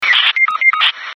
ui-negative-alert_s4bDddz.mp3